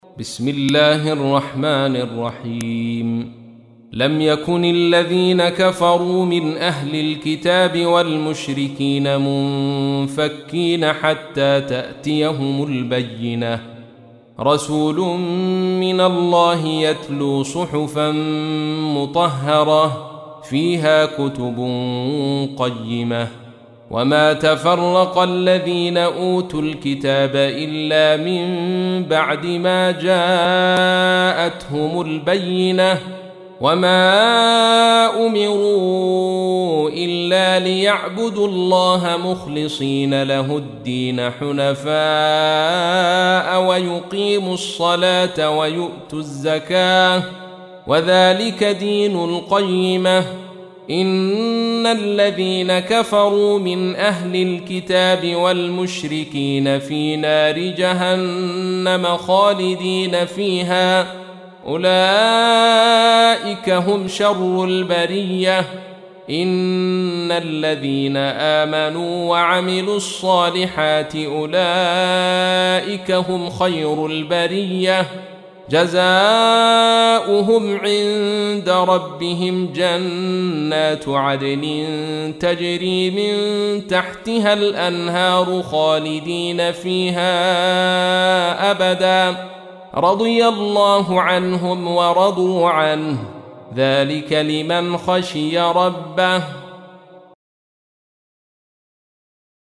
تحميل : 98. سورة البينة / القارئ عبد الرشيد صوفي / القرآن الكريم / موقع يا حسين